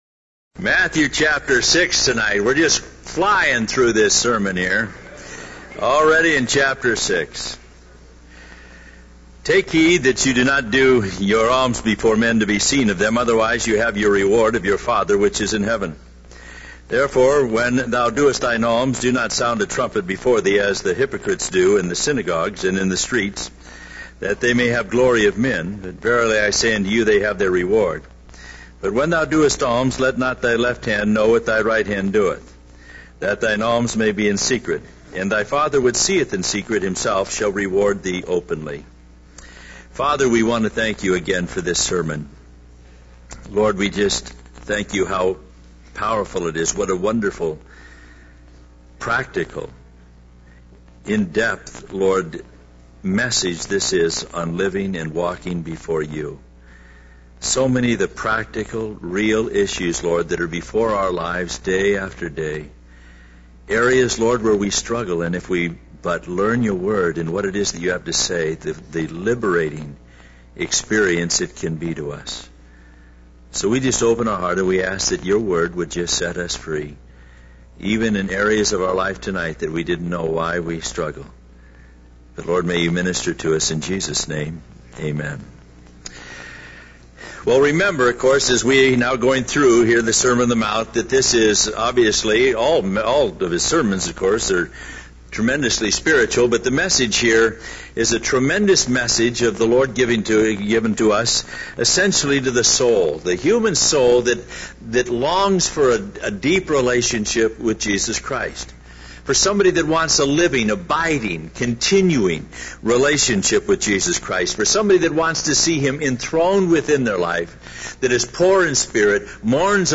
In this sermon, the preacher addresses the tiredness and frustration that people feel towards their sinful nature. He emphasizes the importance of prioritizing one's relationship with God over seeking recognition from others.